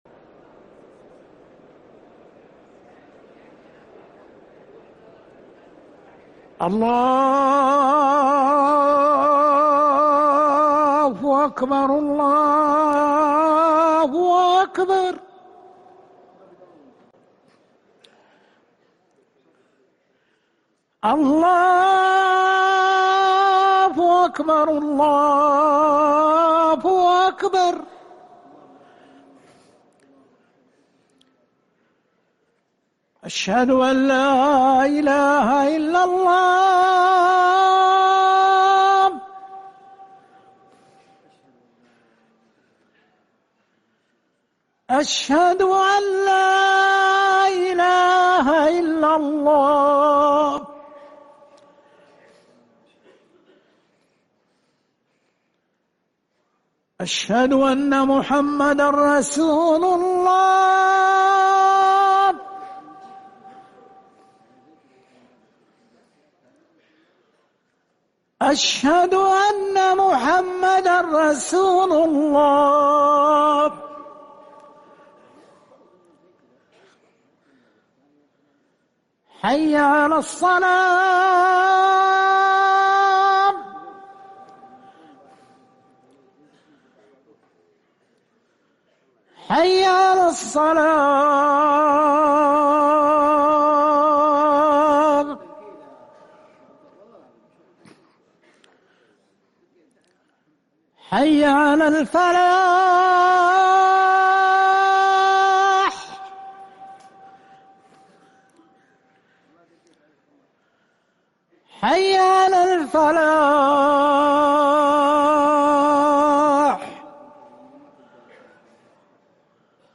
اذان الظهر